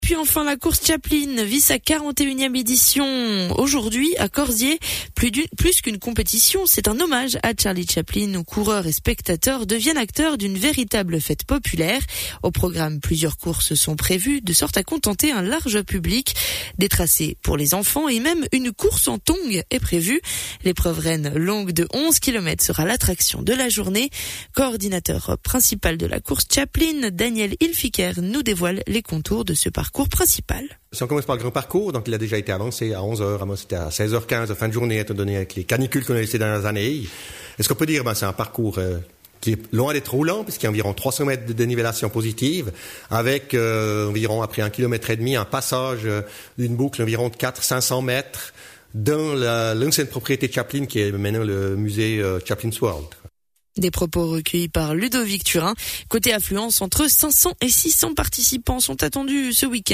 Interview Radio Chablais  2025
interview-radio_chablais-2025.mp3